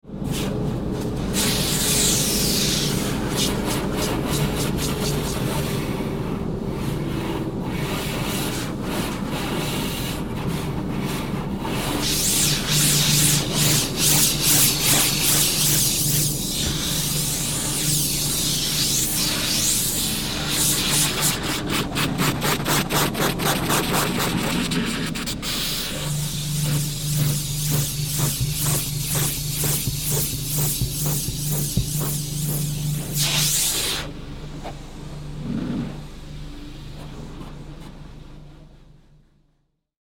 Car Wash: High-Pressure Water Sound Effects
Description: Car wash with high-pessure water sound effect. A clear and realistic recording of a car being washed with high-pressure water.
Car-wash-with-high-pressure-water-sound-effect.mp3